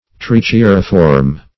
Search Result for " trichiuriform" : The Collaborative International Dictionary of English v.0.48: Trichiuriform \Trich`i*u"ri*form\, a. (Zool.)
trichiuriform.mp3